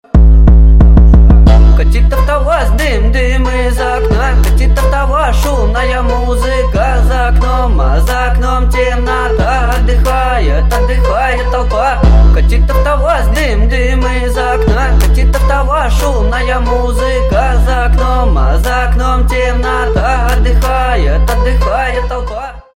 Скачать припев